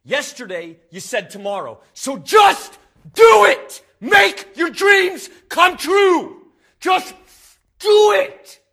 Worms speechbanks
ComeOnThen.wav